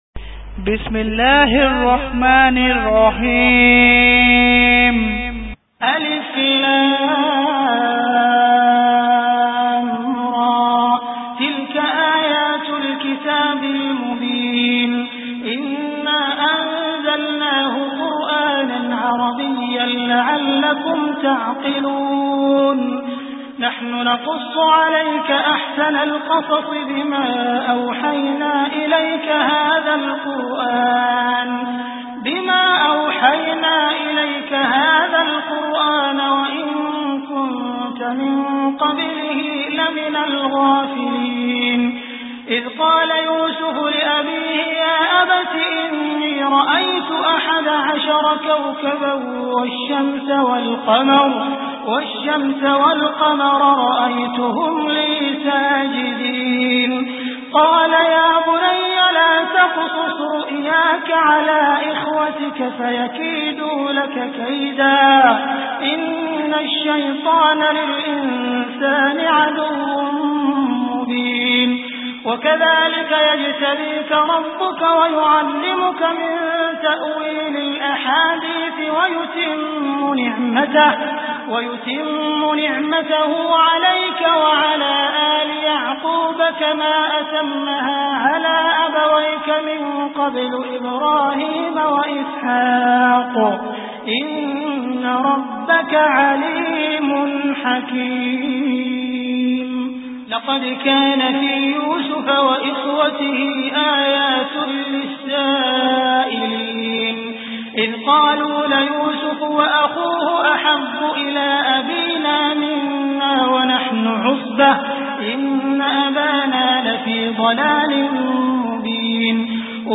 Surah Yusuf Beautiful Recitation MP3 Download By Abdul Rahman Al Sudais in best audio quality.